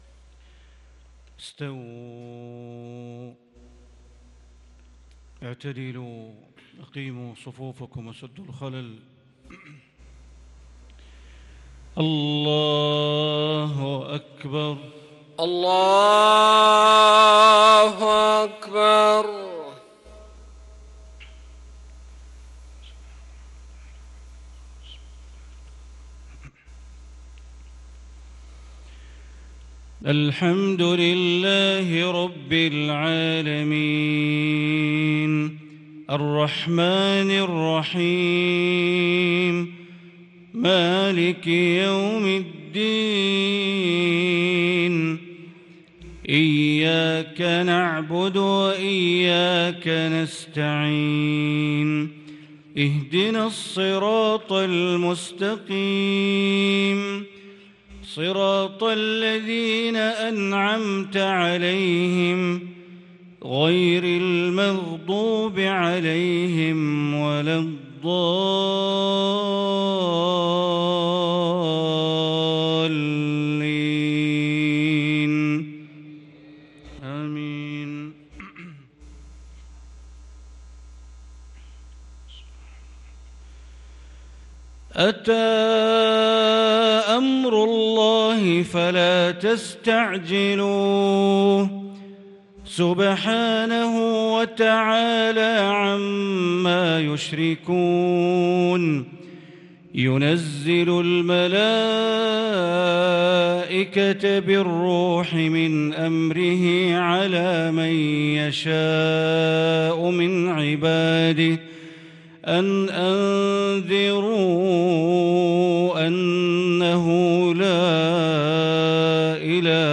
صلاة العشاء للقارئ بندر بليلة 26 ربيع الأول 1444 هـ
تِلَاوَات الْحَرَمَيْن .